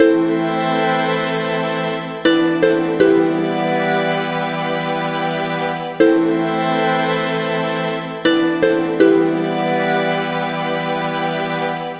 冰冷的钢琴1
标签： 80 bpm Chill Out Loops Piano Loops 1.01 MB wav Key : G
声道单声道